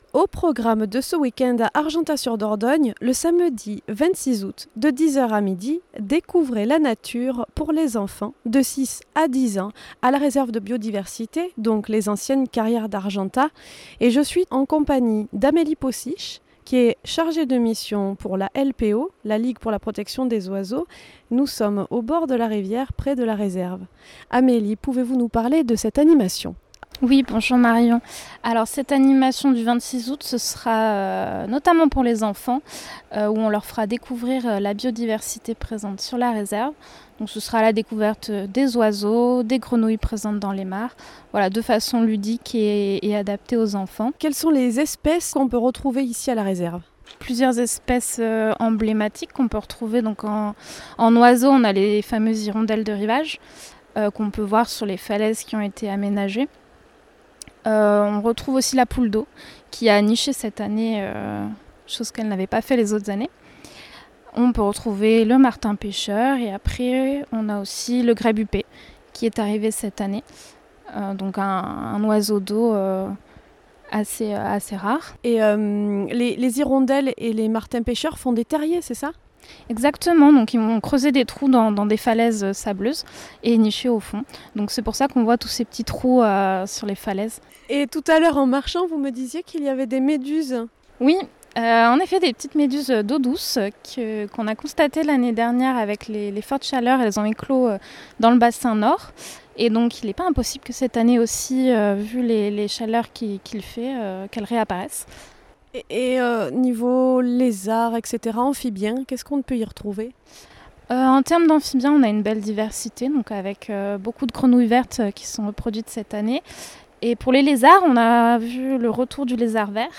Interview biodiversite 2023.
Interview-biodiversite-2023.mp3